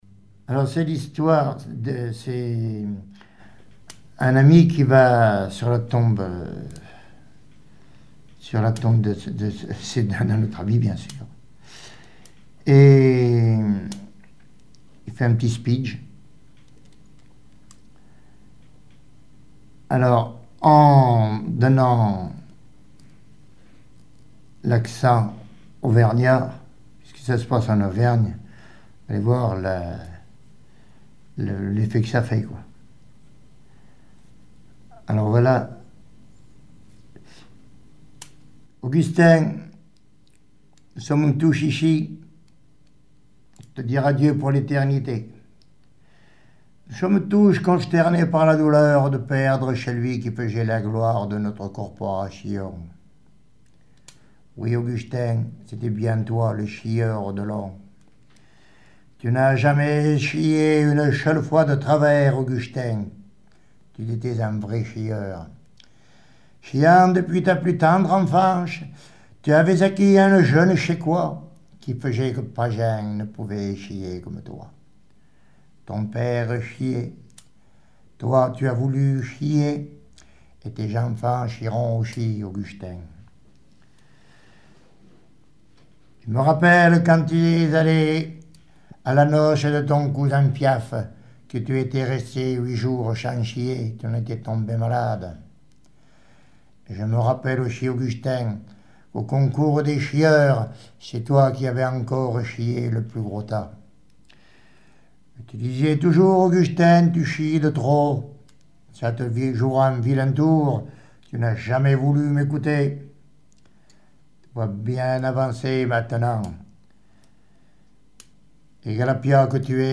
Genre sketch
Enquête Arexcpo en Vendée